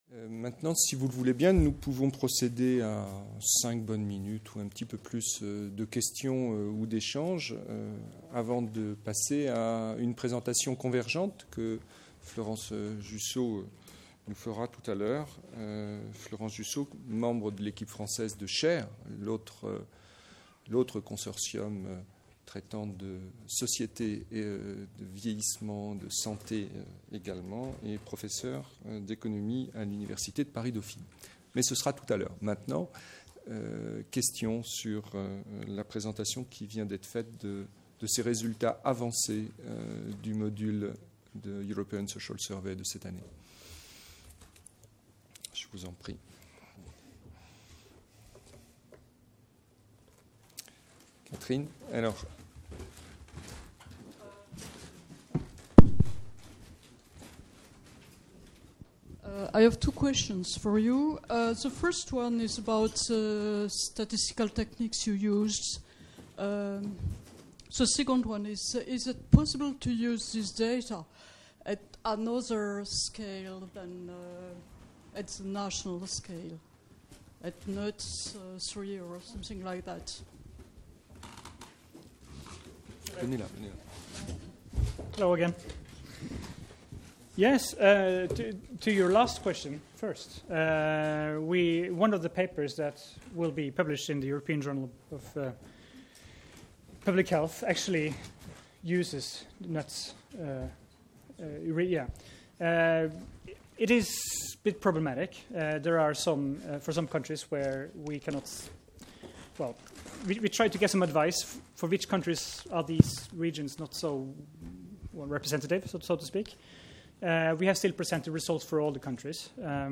ESS TOPLINE RESULTS PRESENTATION